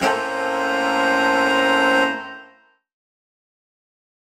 UC_HornSwellAlt_Bmajminb6.wav